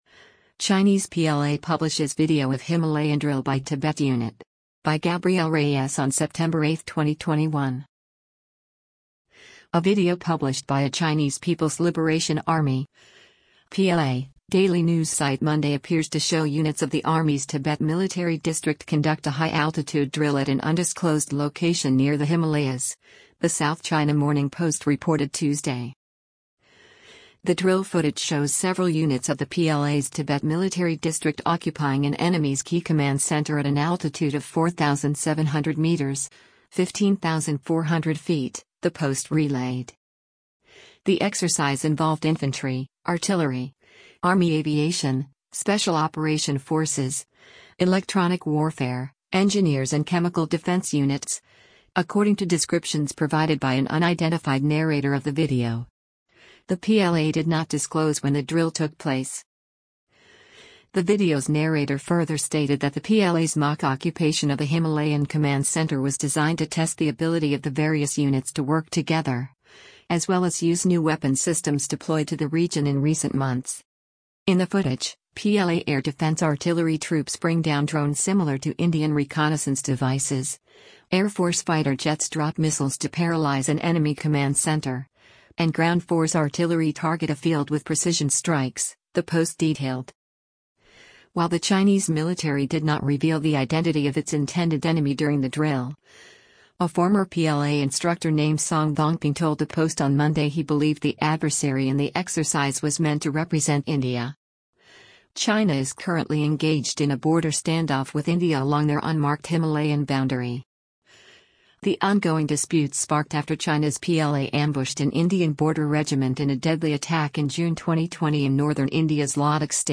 The exercise involved “infantry, artillery, army aviation, special operation forces, electronic warfare, engineers and chemical defense units,” according to descriptions provided by an unidentified narrator of the video.